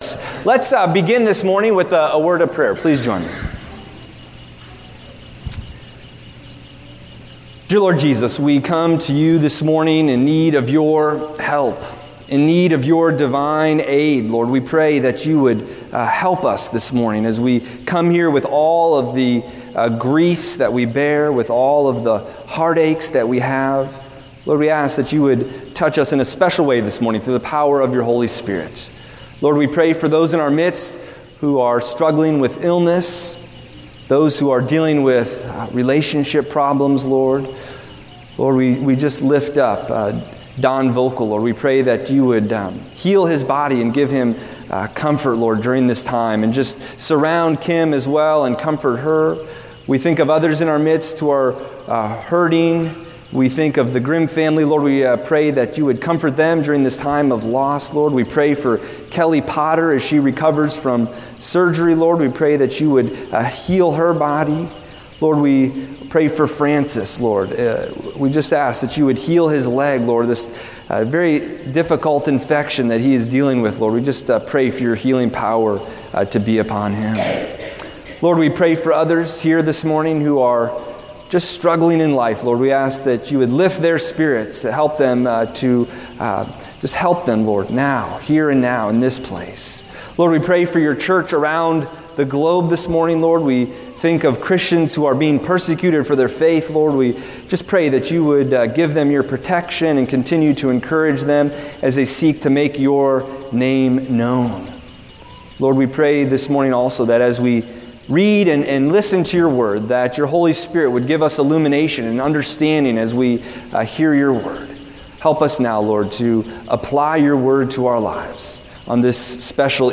Service Type: Easter Service